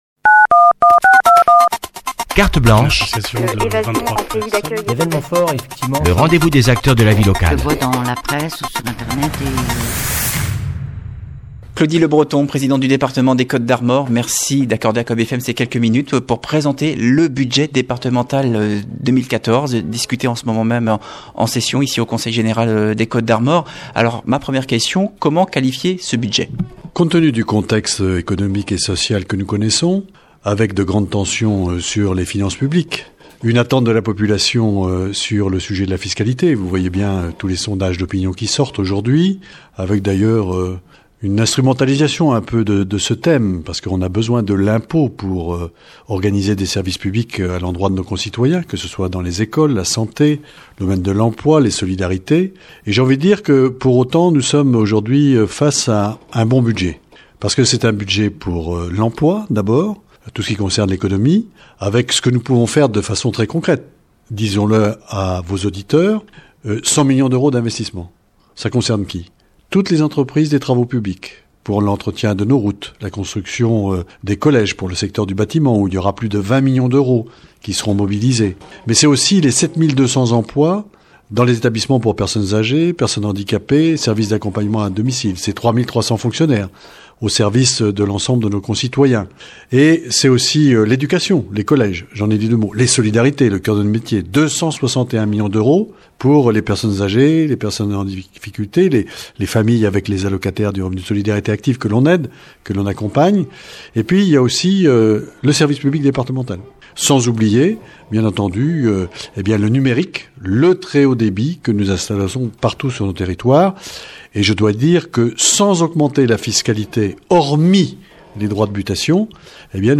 Interview de Claudy Lebreton